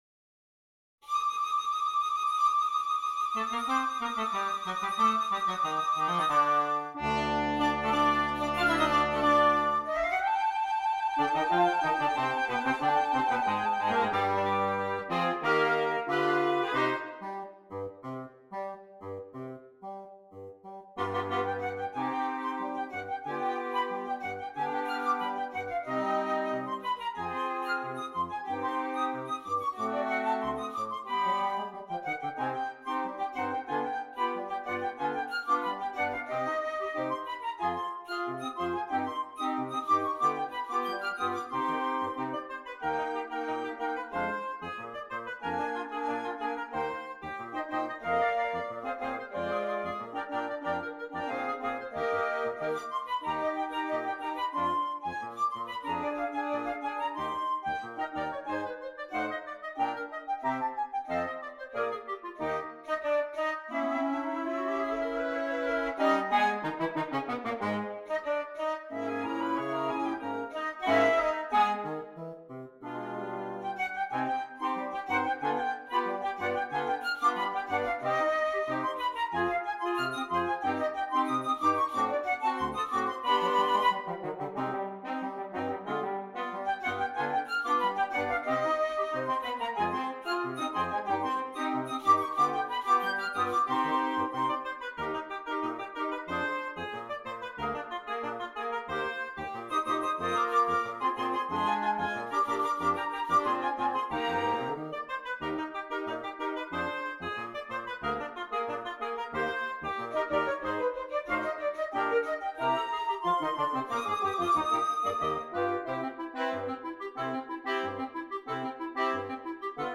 Gattung: Für Holzbläserquintett
Besetzung: Ensemblemusik für 5 Holzbläser